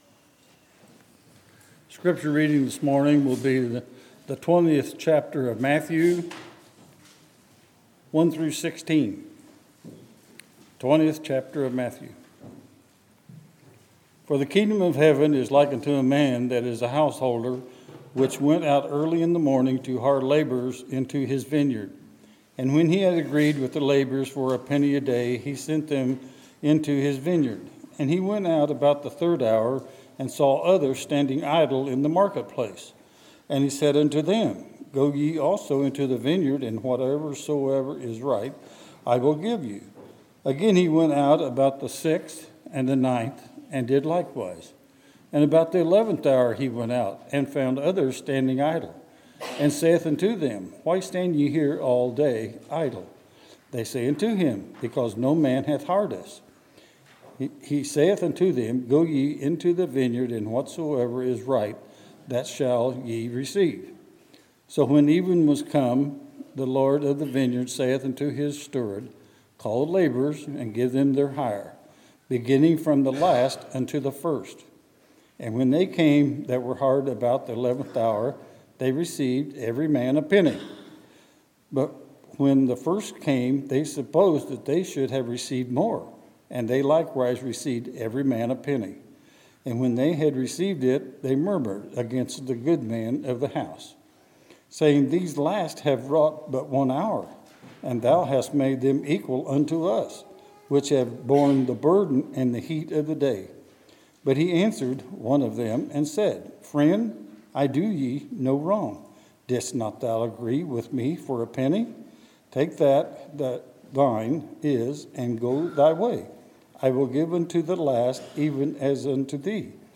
Sermons, October 22, 2017